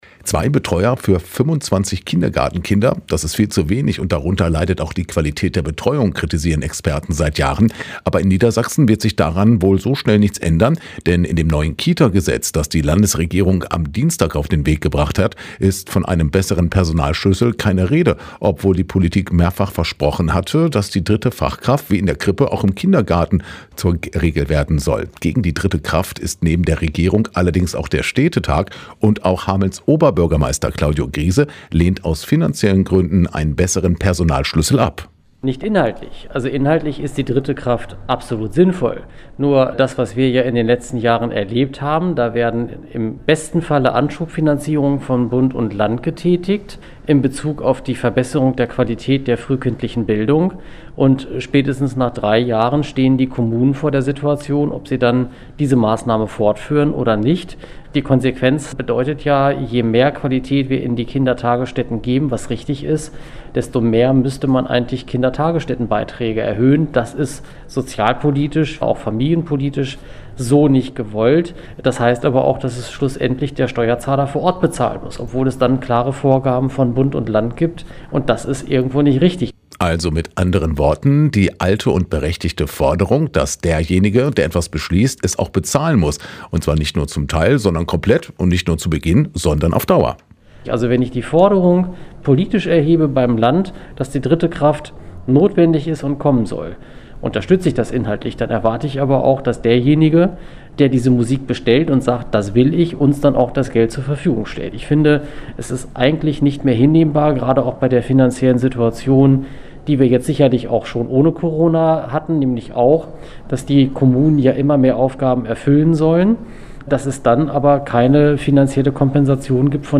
Aktuelle Lokalbeiträge Hameln: KEIN GELD FUER DRITTE KITA KRAFT Play Episode Pause Episode Mute/Unmute Episode Rewind 10 Seconds 1x Fast Forward 30 seconds 00:00 / Download file | Play in new window Hamelns Oberbürgermeister Claudio Griese zur Diskussion um das neue Kitagesetz…